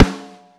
Snare 07.wav